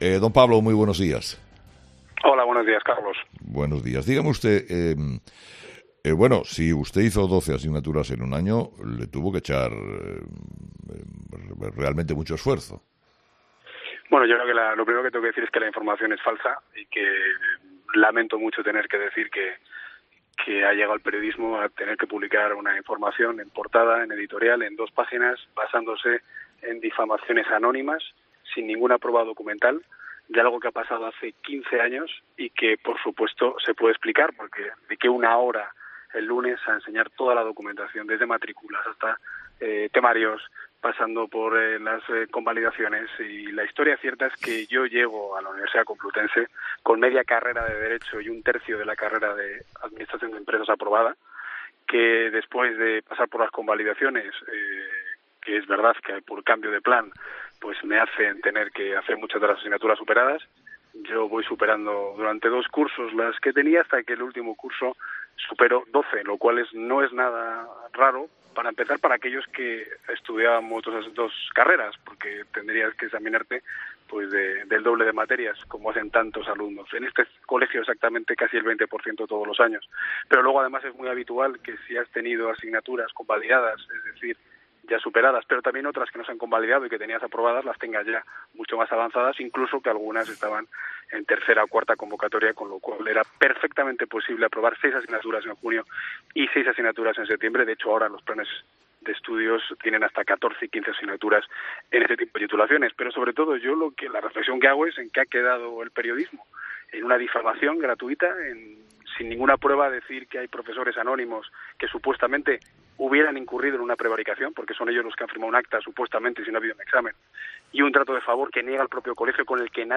Entrevista a Pablo Casado, información de 'El Mundo'